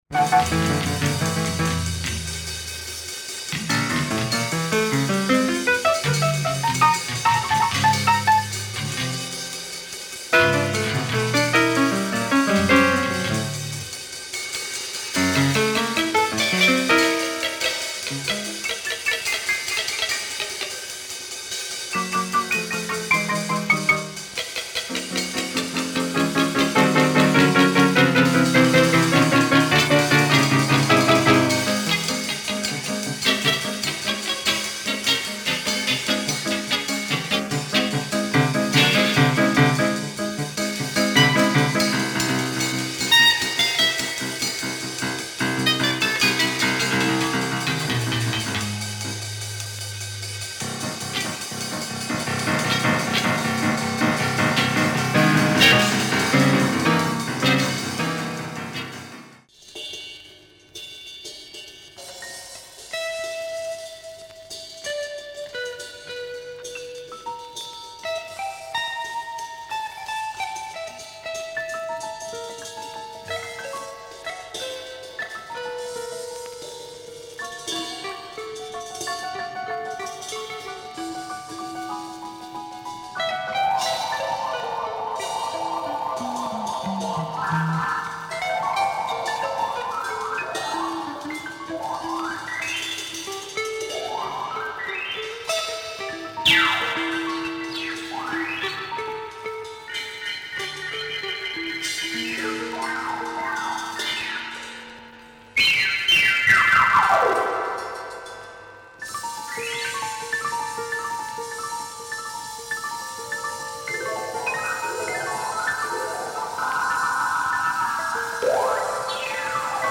Abstract jazz and cinematic / experimental sounds